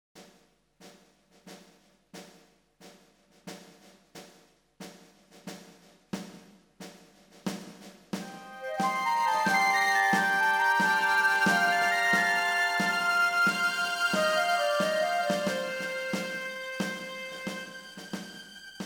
Colonne sonore che fanno parte del gioco.